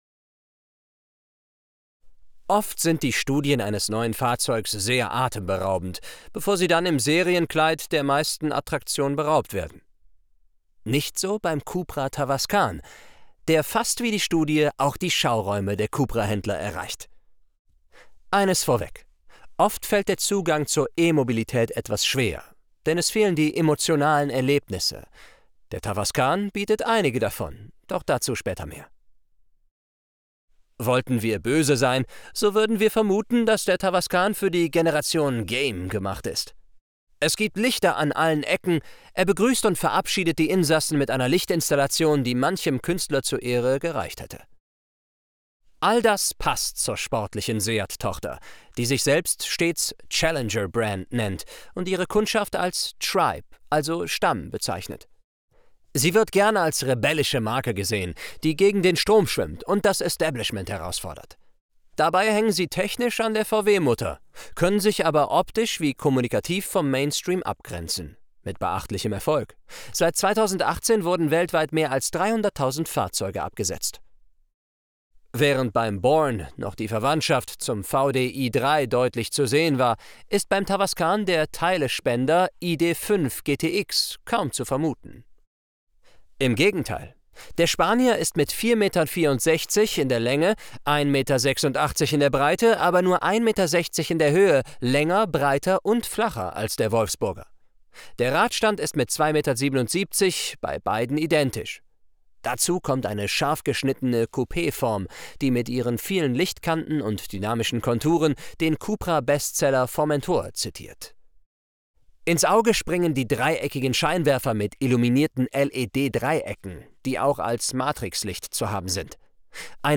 Downloads Deutsch (1080p H.264) Deutsch (720p H.264) Deutsch (360p H.264) Deutsch (Voiceover WAV) Atmo/Clean (1080p H.264) Atmo/Clean (720p H.264) Atmo/Clean (360p H.264)
cupra_tavascan_24_de_vo.wav